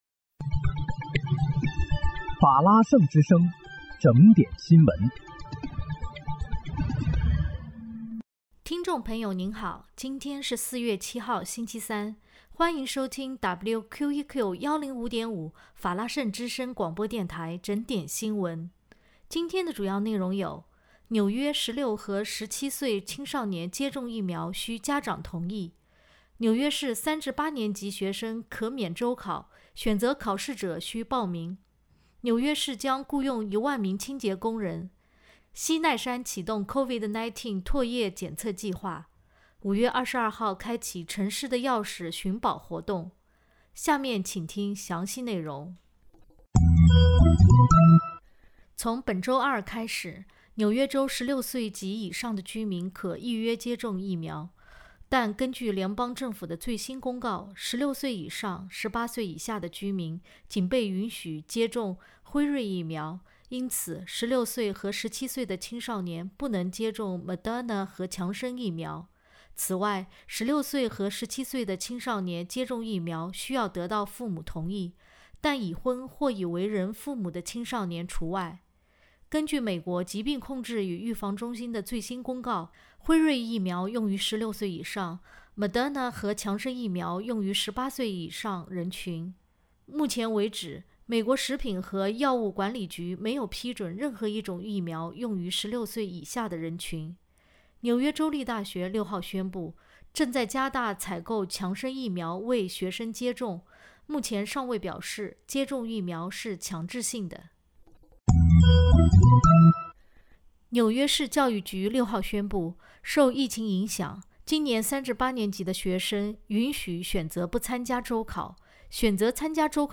4月7日（星期三）纽约整点新闻